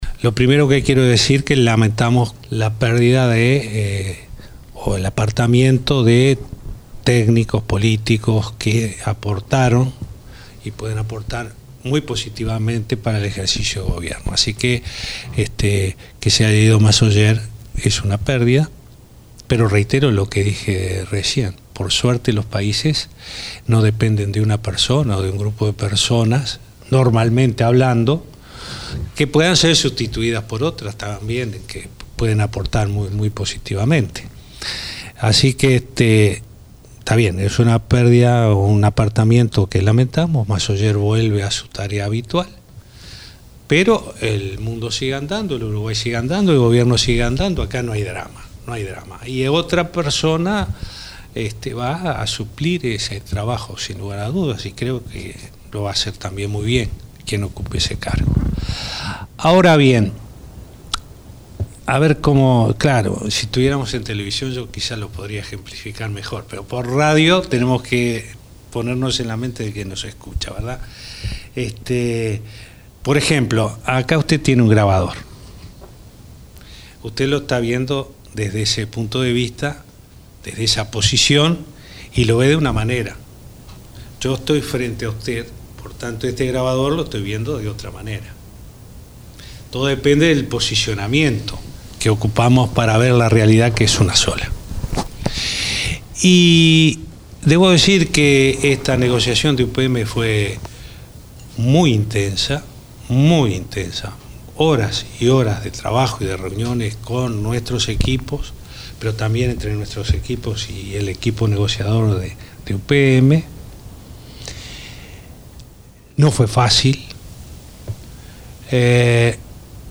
En una entrevista radial, el presidente Tabaré Vázquez adelantó que la semana que viene estaría cerrado el acuerdo con UPM.